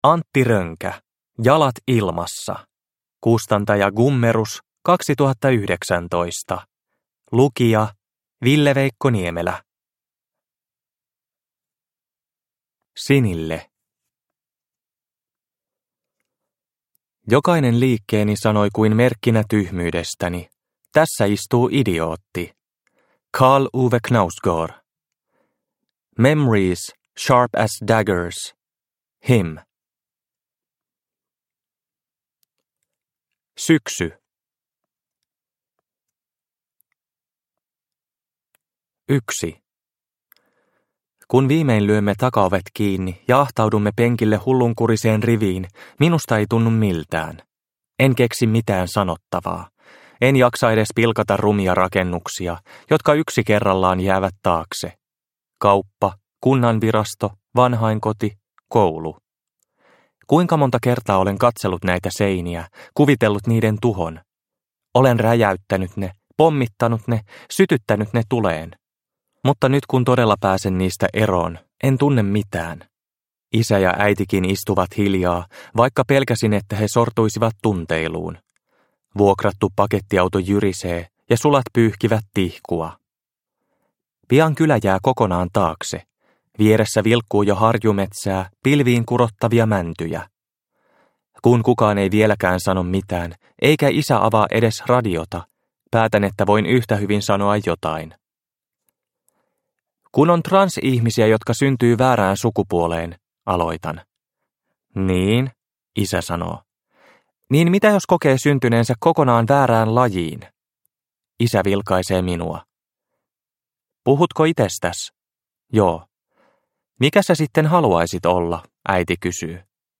Jalat ilmassa – Ljudbok – Laddas ner